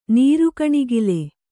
♪ nīru kaṇigali